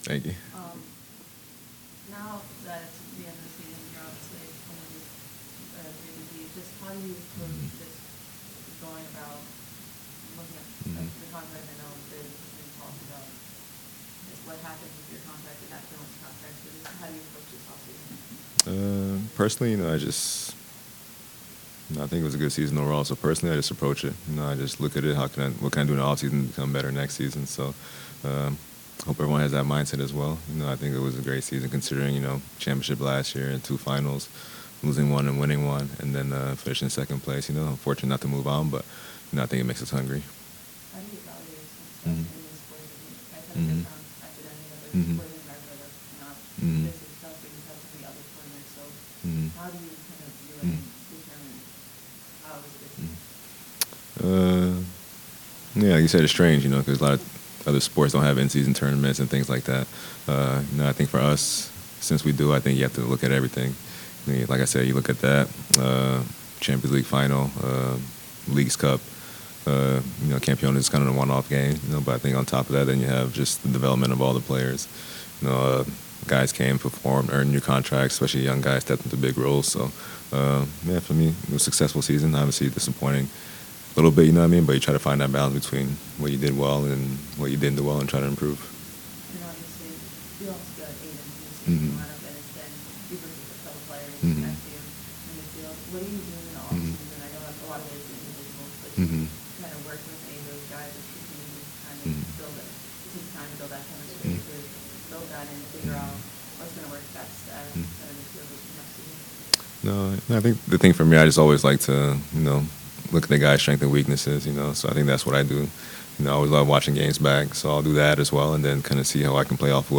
Darlington Nagbe Crew midfielder & captain On 2024 season analysis and looking ahead to 2025
NAG-bee